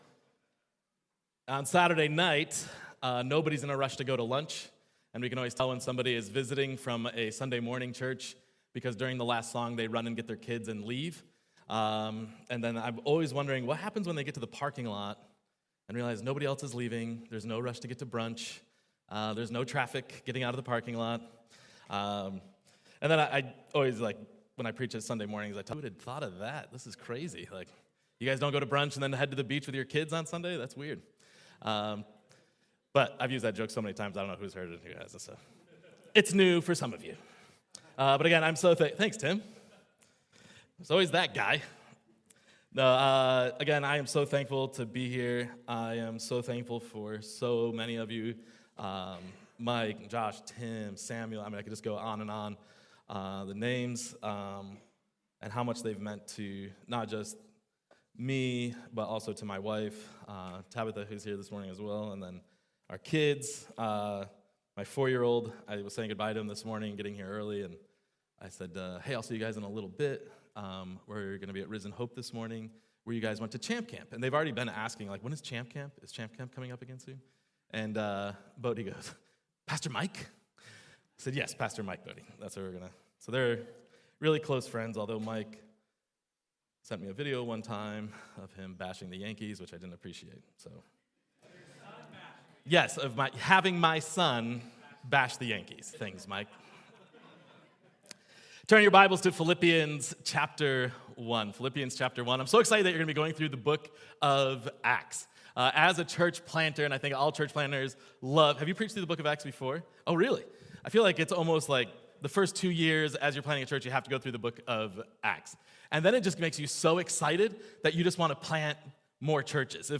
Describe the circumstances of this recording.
These sermons have been preached outside a normal sermon series at Risen Hope.